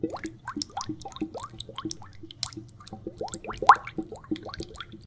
action_fillwater_0.ogg